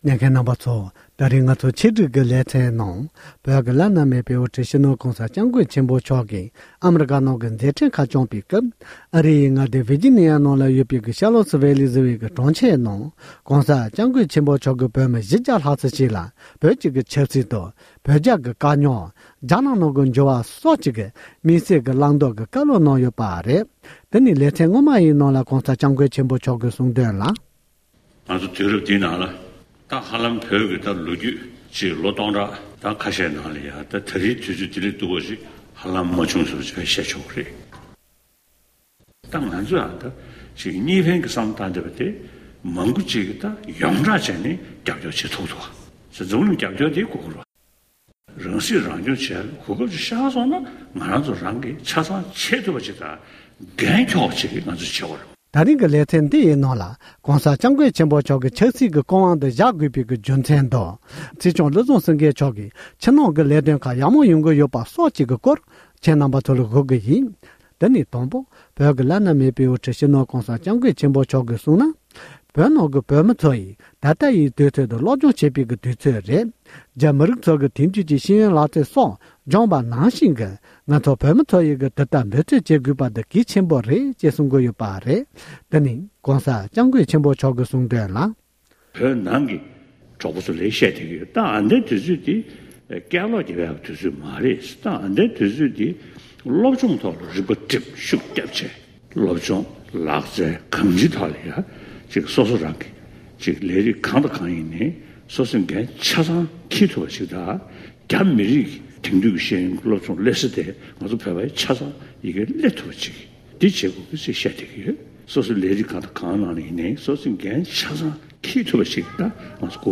ཝར་ཇི་ནི་ཡའི་ཁུལ་གྱི་བོད་མི་ཚོར་དམིགས་བསལ་མཇལ་ཁ་དང་བཀའ་སློབ་གནང་བ།